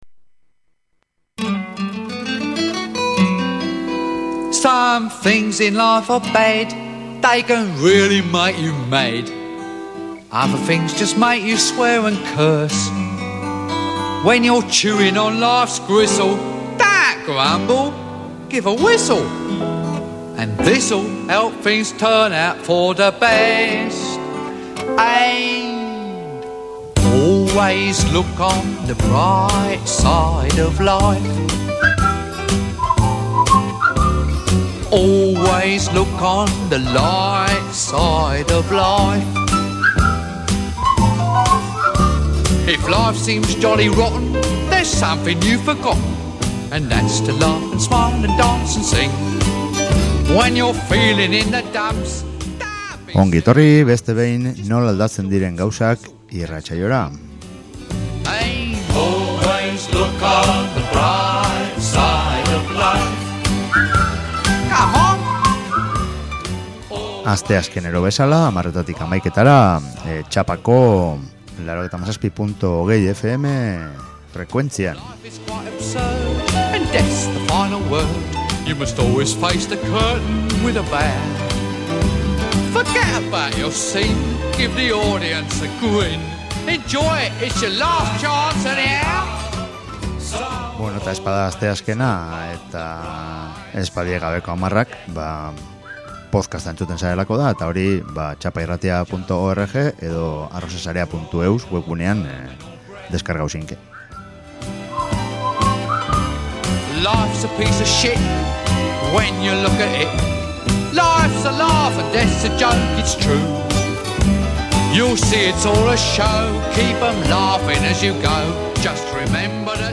amazoniako doinuak